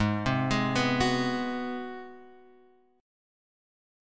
G#7#9 chord